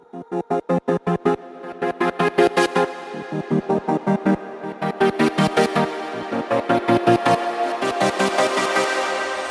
Не подскажите, как такое сделать силами Студии1? Это записаны аккорды 1\16 нотками, потом руками нарисована пила изменения громкости и потом на синте автоматизация кат офф?